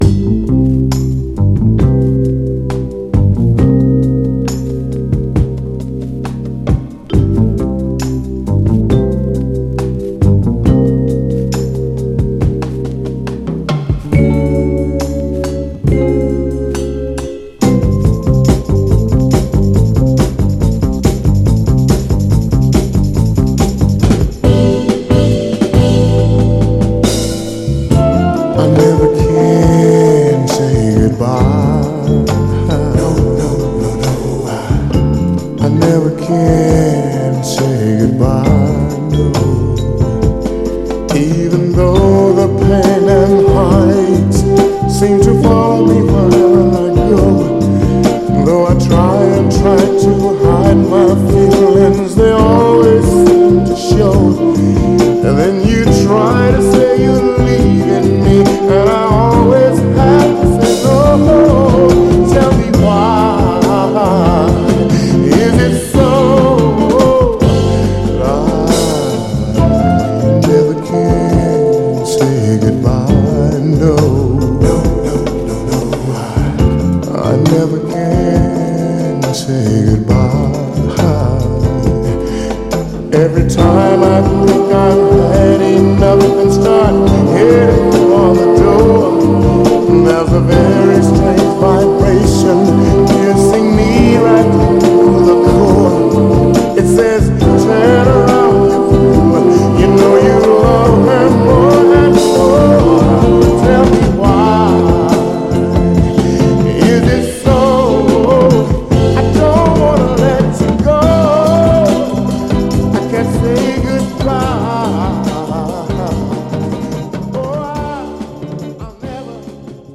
美しいヴァイブが効いたバッキングもカッコいいですよね。
※試聴音源は実際にお送りする商品から録音したものです※